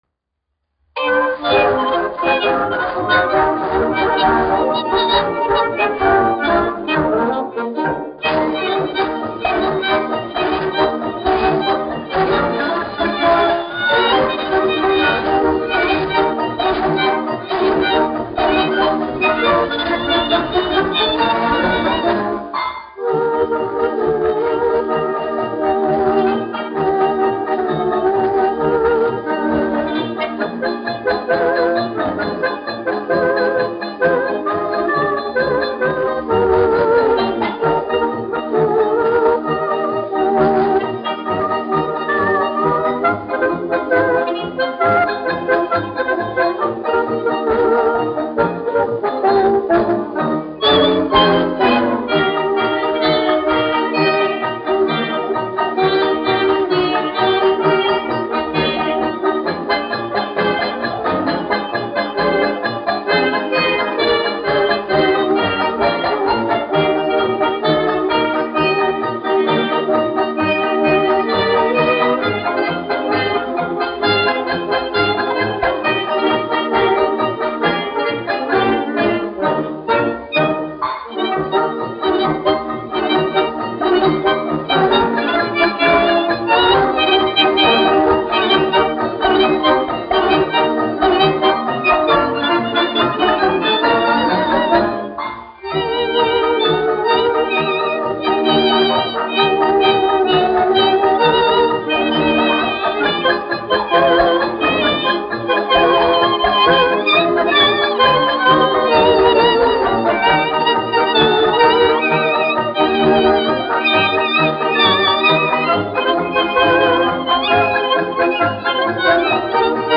1 skpl. : analogs, 78 apgr/min, mono ; 25 cm
Kinomūzika
Marši
Skaņuplate